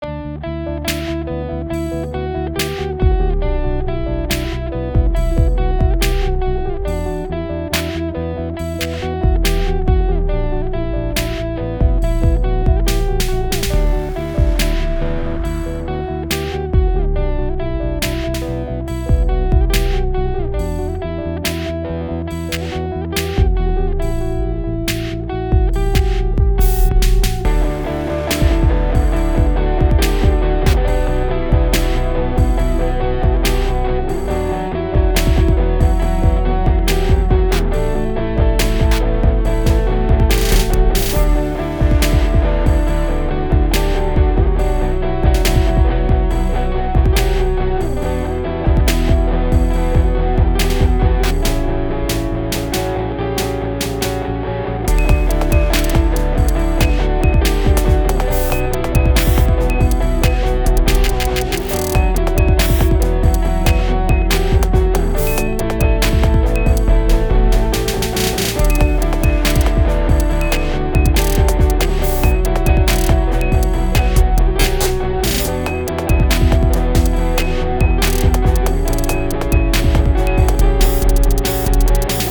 Download Loop Download GarageBand Archive TAGS: optimistic